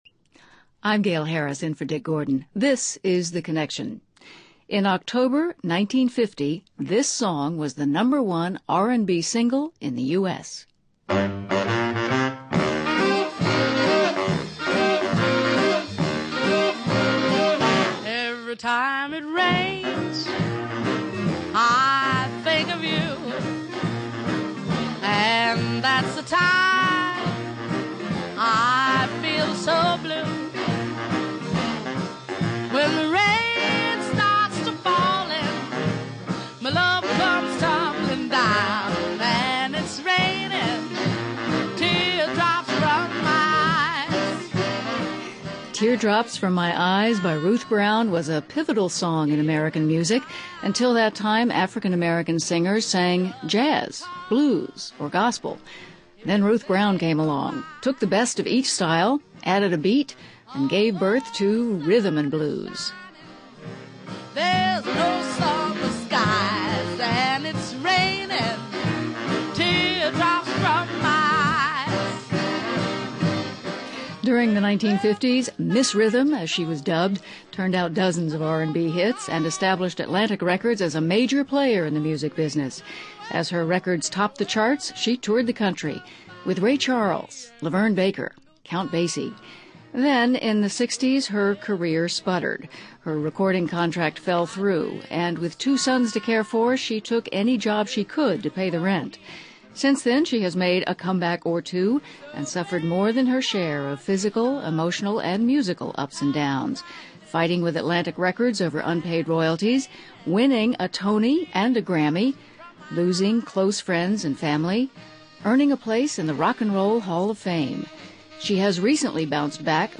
Guests: Ruth Brown, Grammy and Tony Award winning singer.